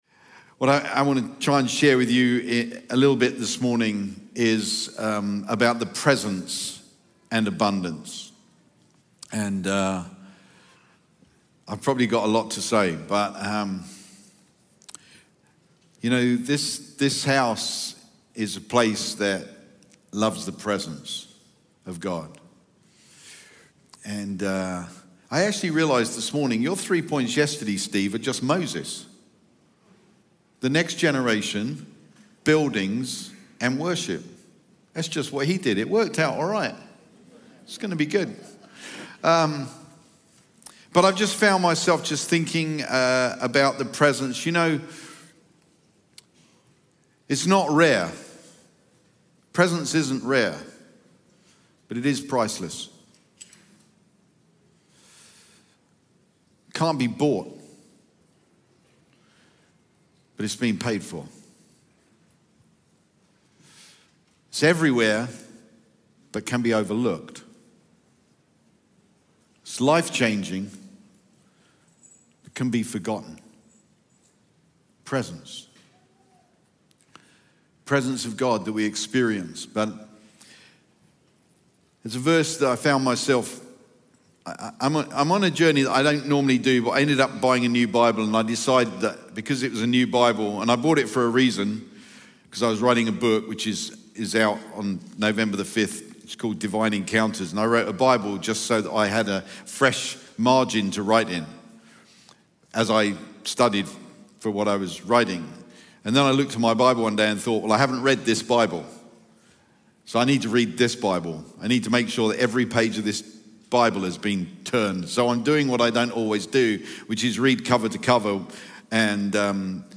Sunday Sermon Abundance & The Presence of God
Chroma Church Live Stream